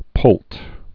(pōlt)